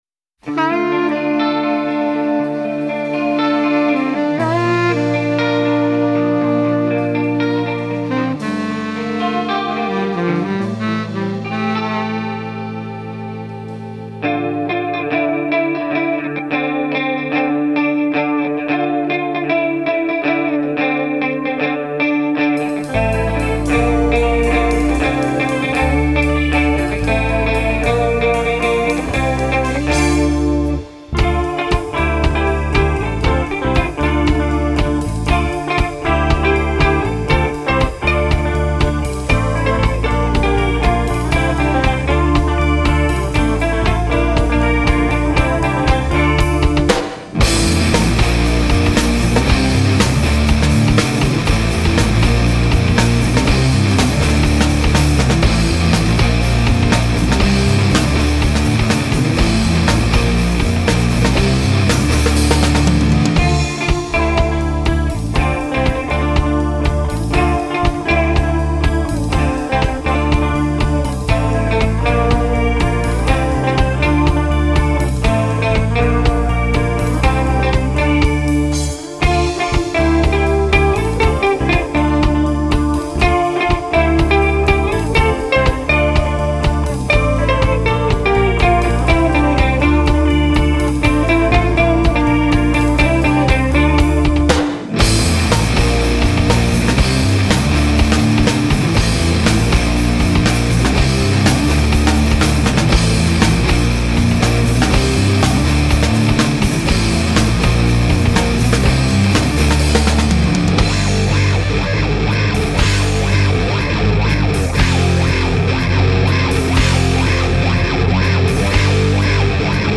Žánr: Rock
baskytara, zpěv
saxofon
instrumentální